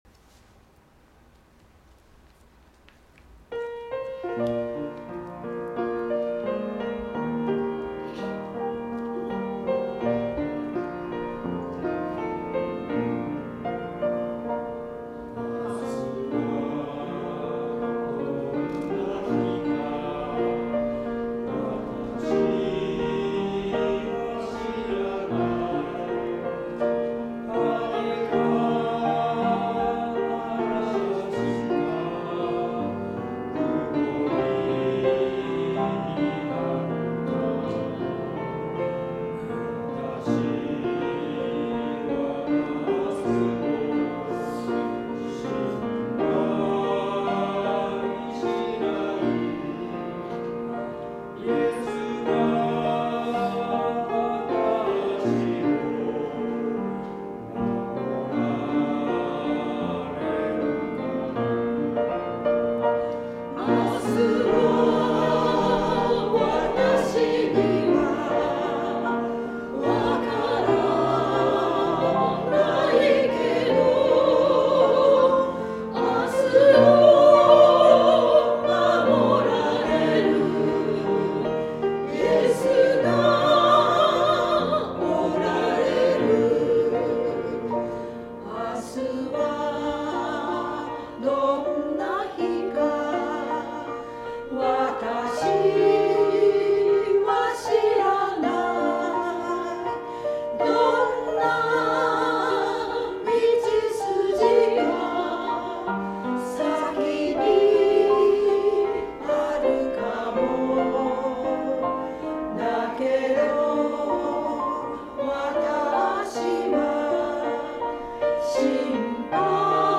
1月25日聖歌隊賛美「明日はどんな日か」